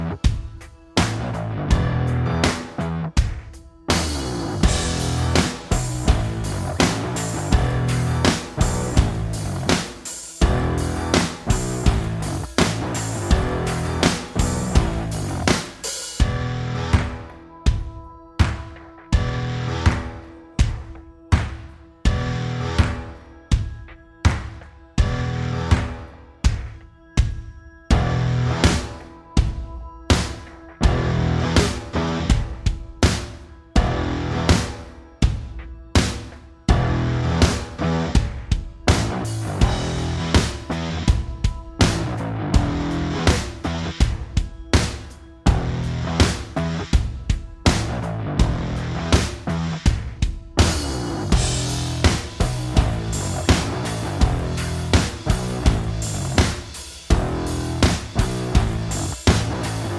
Minus Main Guitars For Guitarists 2:59 Buy £1.50